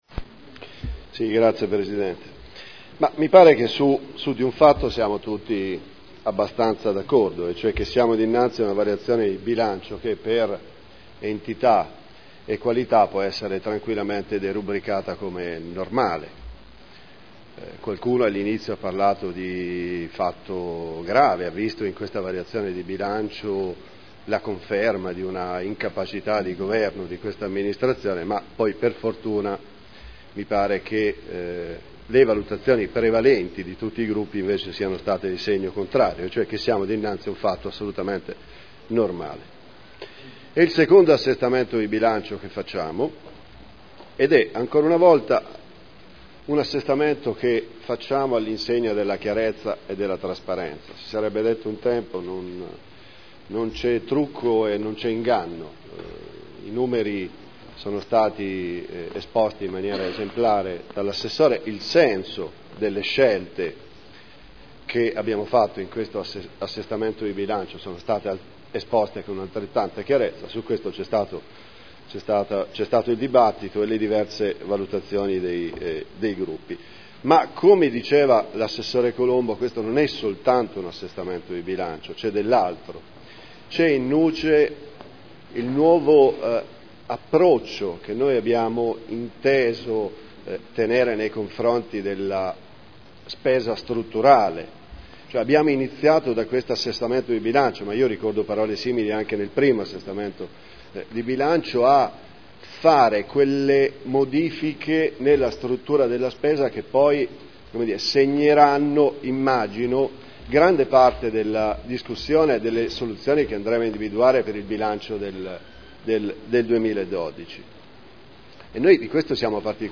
Seduta del 26 settembre 2011 Bilancio di Previsione 2011 - Bilancio Pluriennale 2011-2013 - Programma triennale dei Lavori Pubblici 2011-2013 - Stato di attuazione dei programmi e verifica degli equilibri di bilancio - Variazione di Bilancio n. 2 Dichiarazione di voto